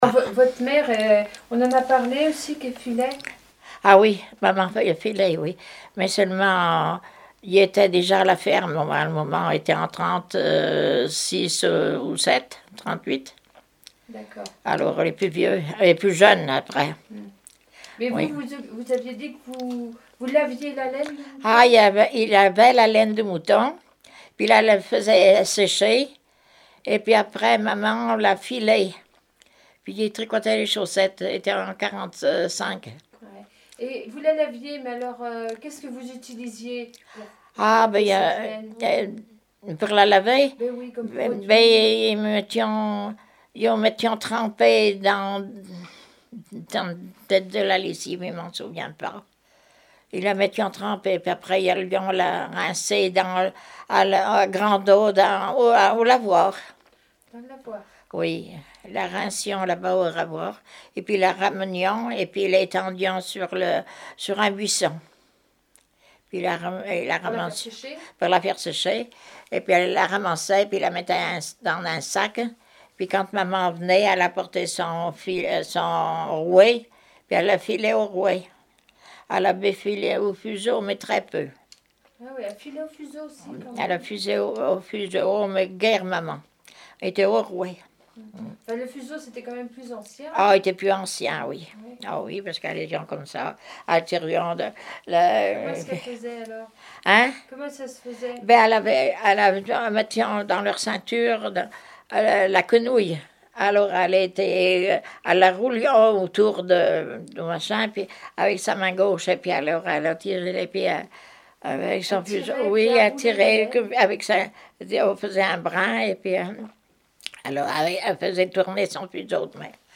Témoignage sur la vie de l'interviewé(e)